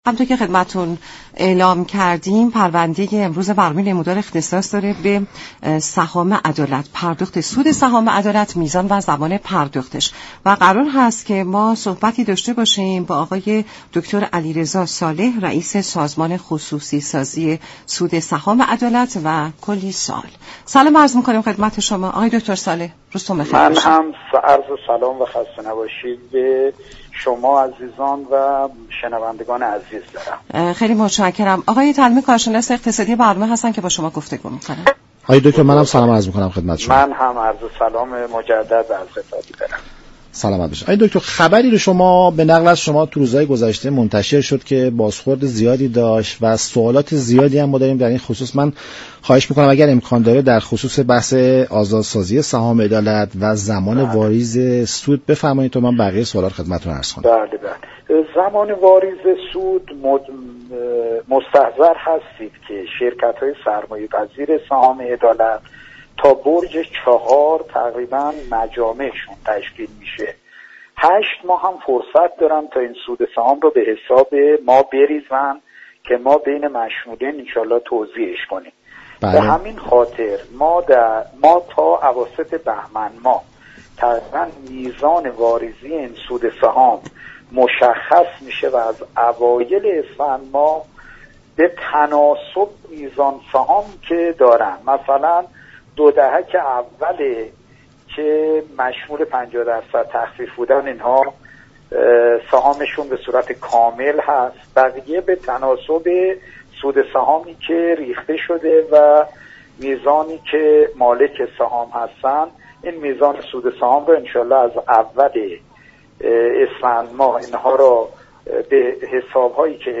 به گزارش شبكه رادیویی ایران، دكتر «علیرضا صالح» معاون وزیر اقتصاد و رییس سازمان خصوصی سازی در گفت و گو با برنامه «نمودار» درباره آزاد سازی سهام عدالت و زمان واریز سود آن گفت: میزان سود سهام عدالت تا اواسط بهمن ماه مشخص می شود و در اسفند ماه سال جاری نیز هر فرد به تناسب میزان سهام خود، سود دریافت می كند.